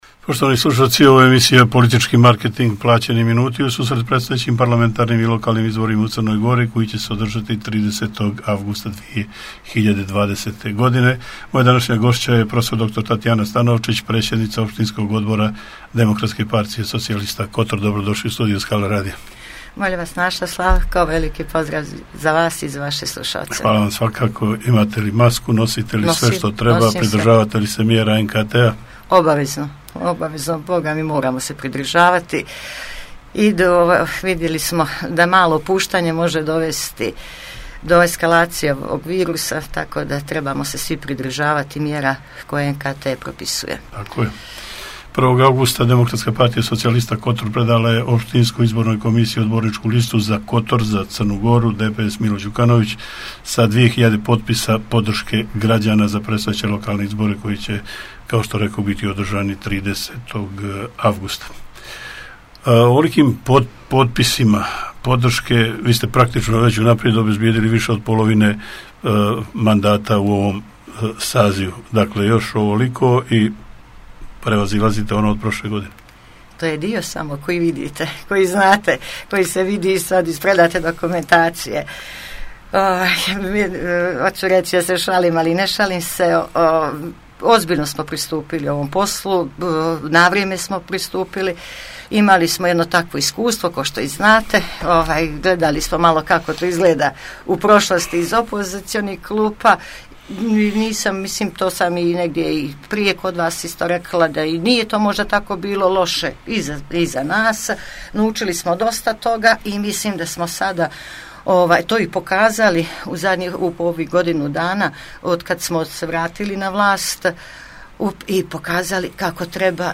Politički marketing, plaćeni minuti.